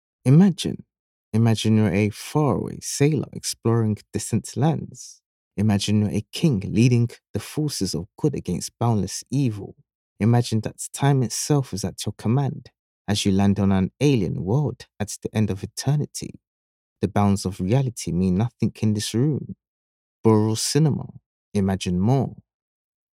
British Voice Over Artists, Talent & Actors
English (Caribbean)
Adult (30-50) | Yng Adult (18-29)